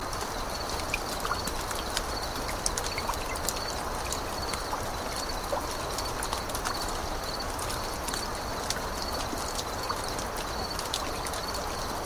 crickets.ogg